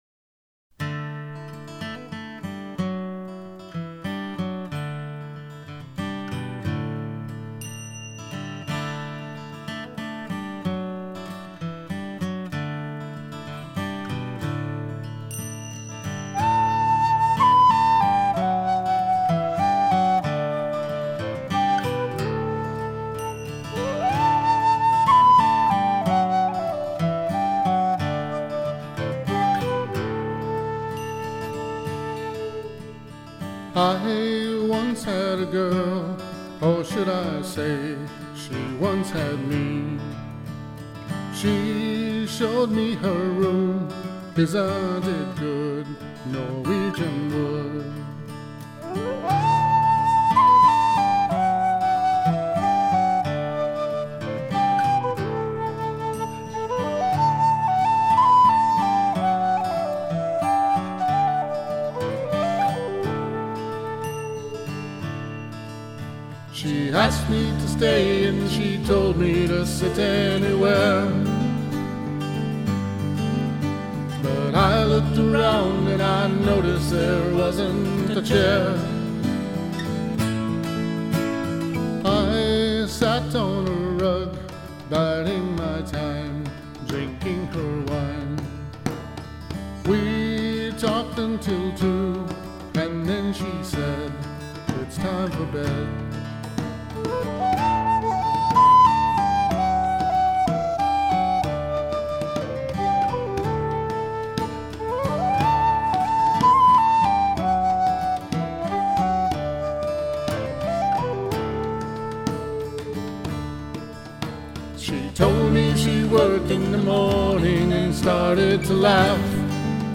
acoustic band
Irish whistle melodies, keyboards and a rockin' sax